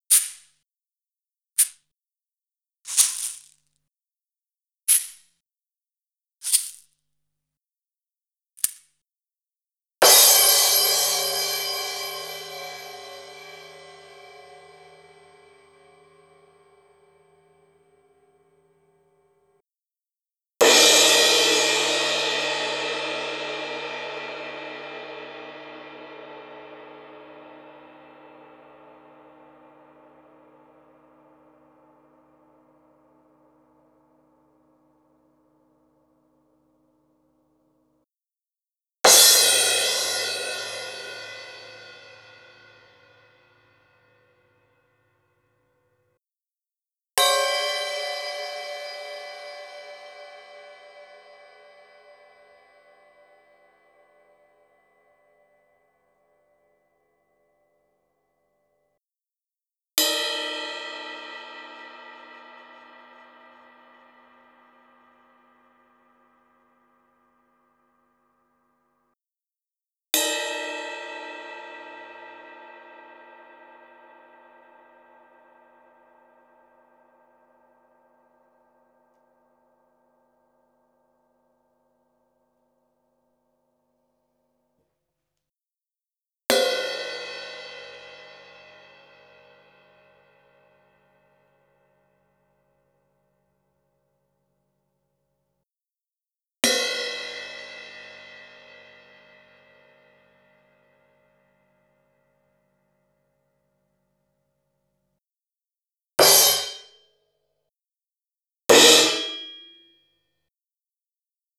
13_shaker.wav